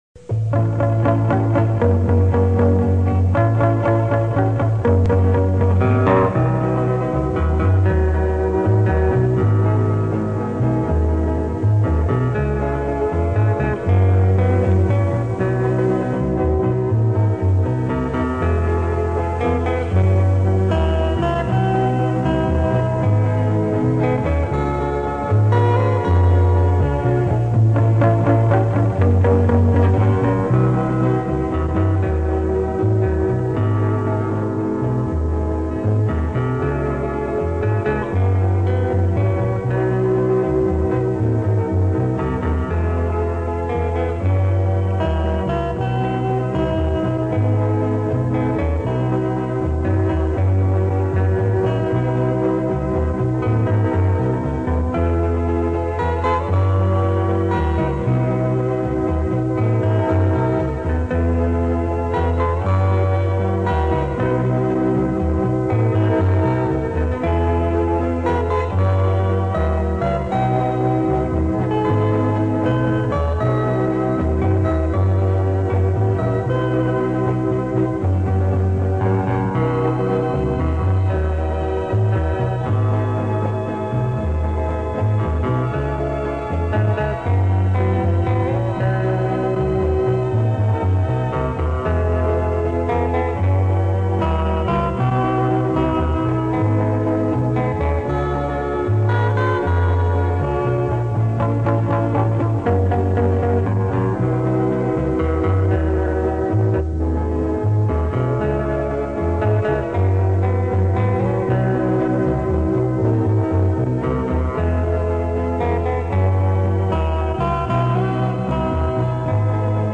instrumentale låter
Den tok over den posisjonen  -  en lang og vakker melodi, hva har ikke verden gått glipp av?
I mars 1965 spilte vi inn ni melodier på bånd